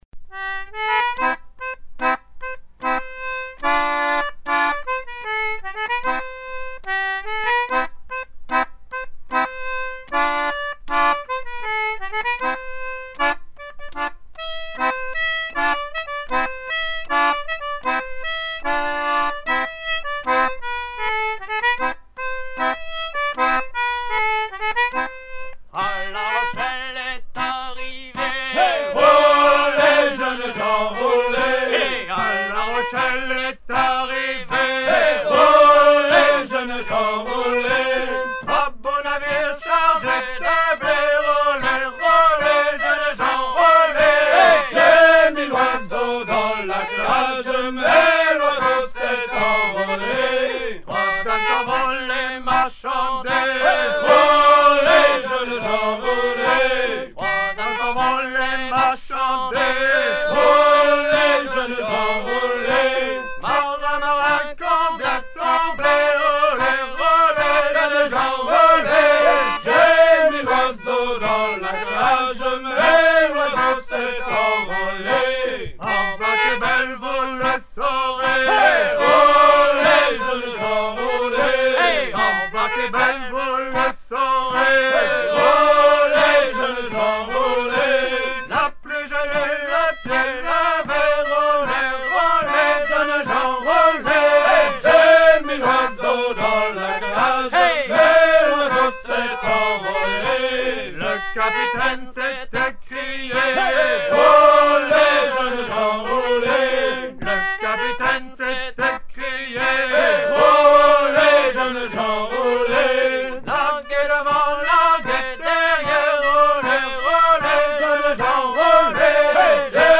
à virer au cabestan
Chants de marins traditionnels des côtes de France